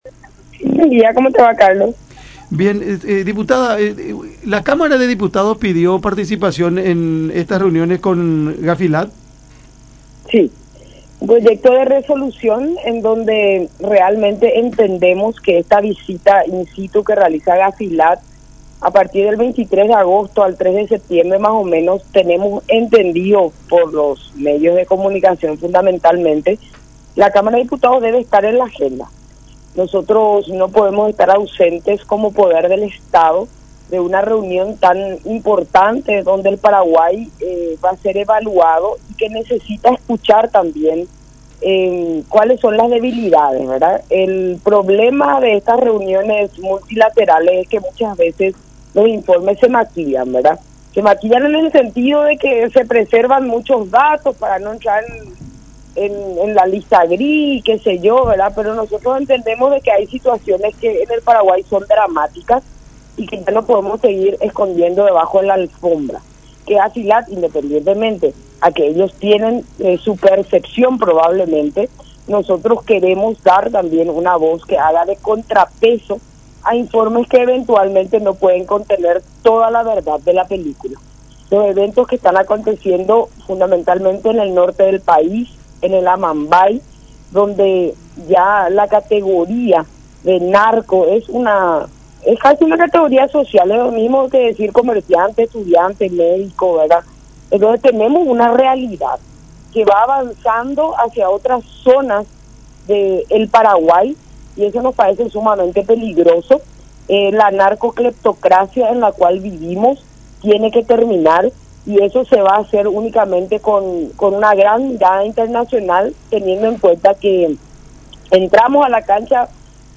“La Cámara de Diputados tiene que estar en la agenda. No podemos estar ausentes como poder del Estado de esta reunión tan importante. No podemos seguir escondiendo algunas cuestiones debajo de la alfombra y debemos mostrar cuáles son las debilidades. En ese sentido, queremos dar voces que hagan de contrapeso”, expresó González en conversación con Cada Mañana a través de La Unión, cuestionando que en la agenda de GAFILAT inicialmente solo están instituciones dependientes del Poder Ejecutivo.